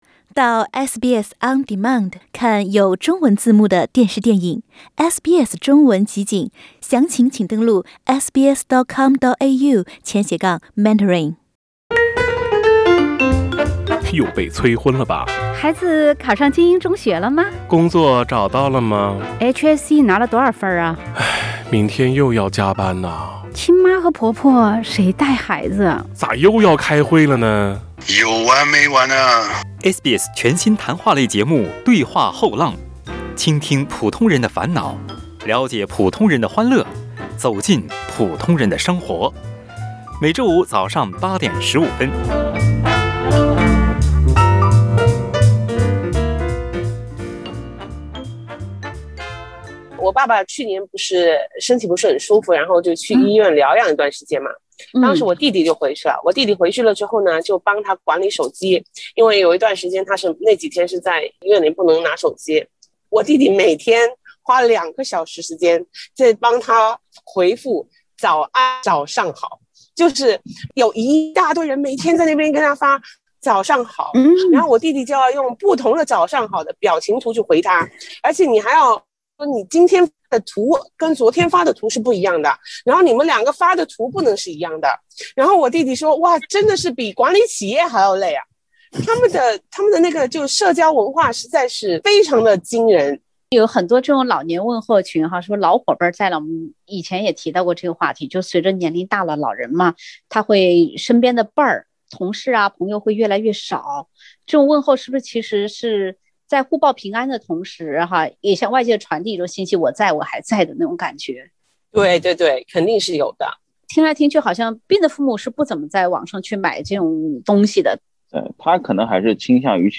（点击封面图片，收听“后浪”有趣谈话）